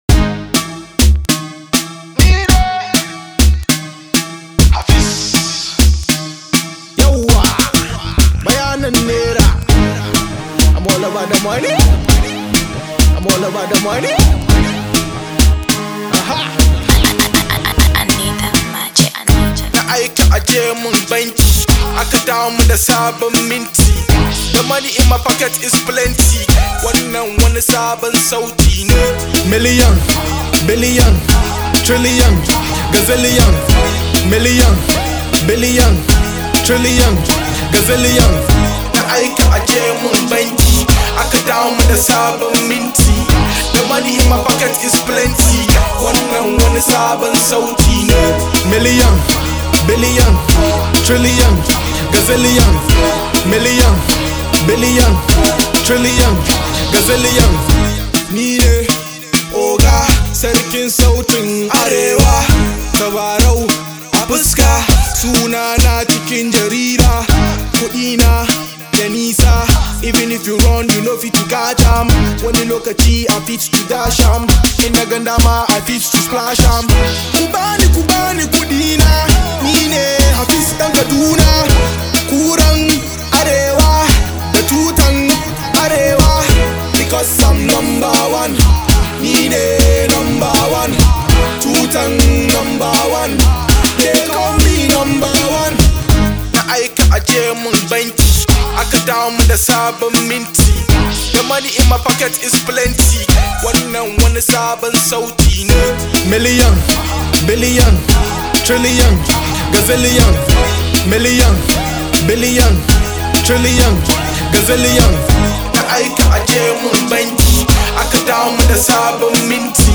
Hausa Pop